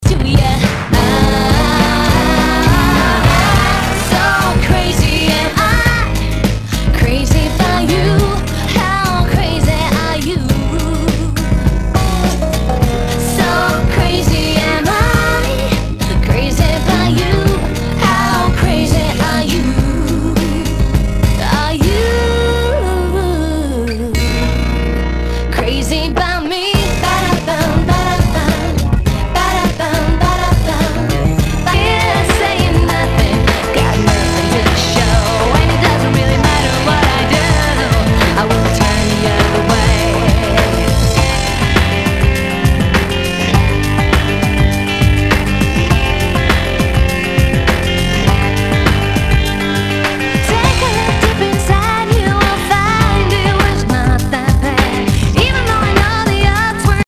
HOUSE/TECHNO/ELECTRO
ナイス！ユーロ・ヴォーカル・ハウス / ダウンテンポ！